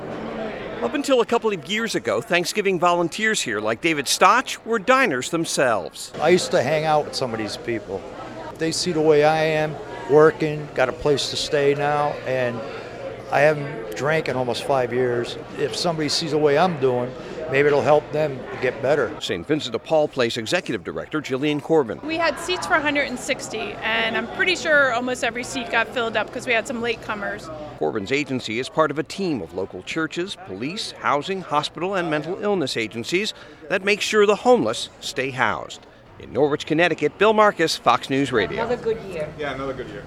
HAS MORE FROM NORWICH, CONNECTICUT: